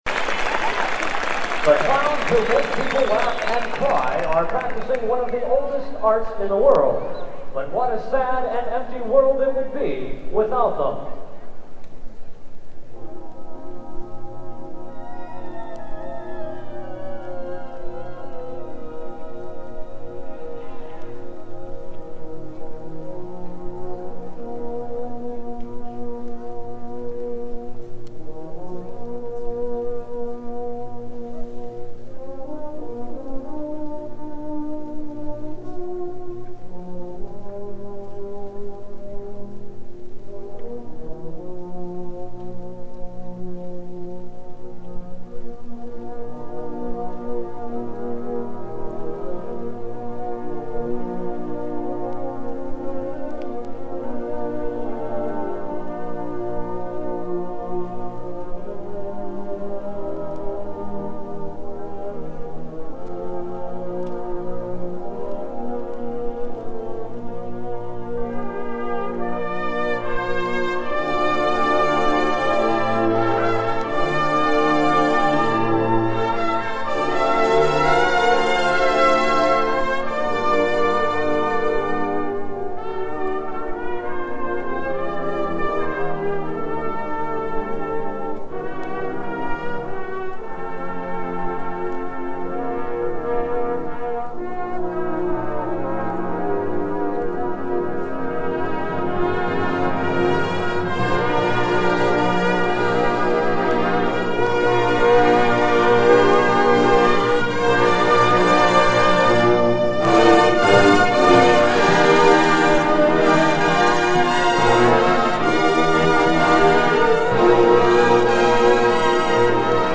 There was no recording made of the post game performance, but below is a link to the All-Western Awards performance. The magic wasn't there, but it was still a fine performance and should give you a clue to how nice the arrangement was.